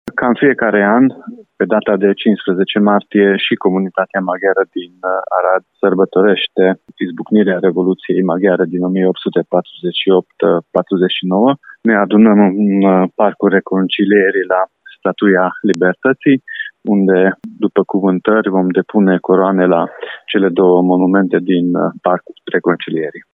Cu această ocazie, la Arad, sunt programate evenimente ample, spune liderul filialei UDR, deputatul Peter Farago.